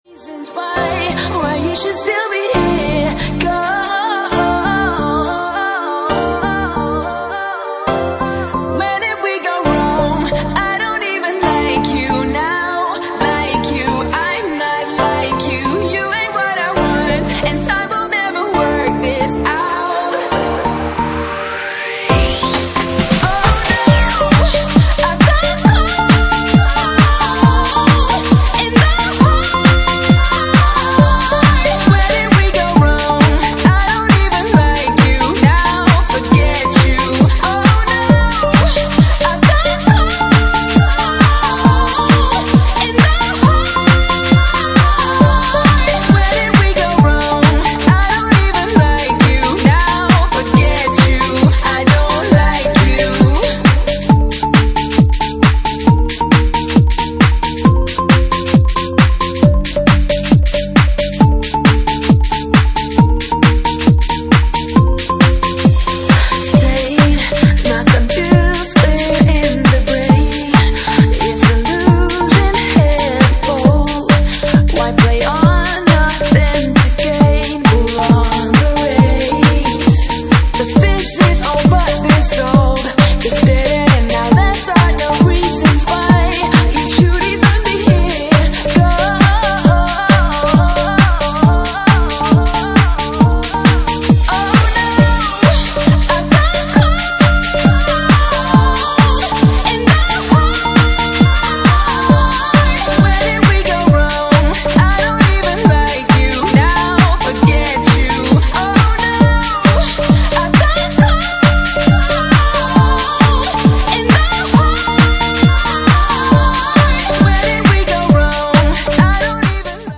Another Big Organ/Piano Remix Check It Out